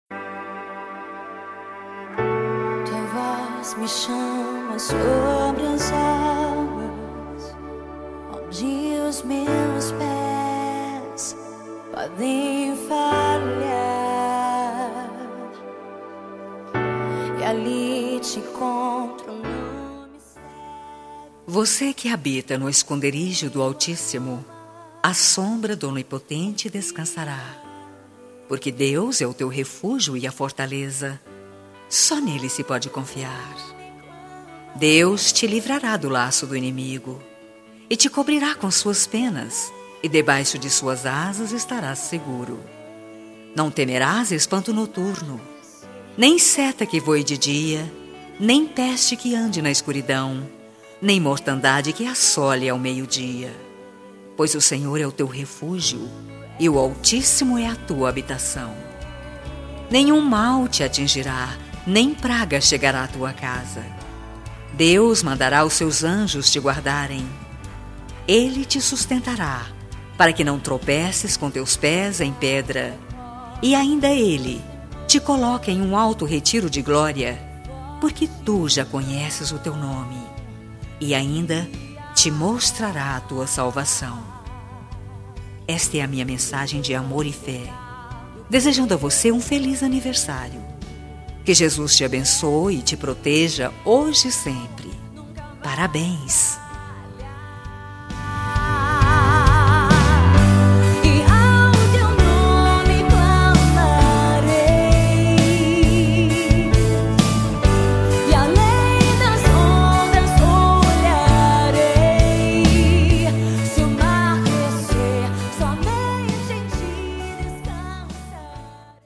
Voz Feminina
Código: 040404 – Música: Oceanos – Artista: Ana Nobrega